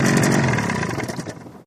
20 hp Johnson Boat Shut Off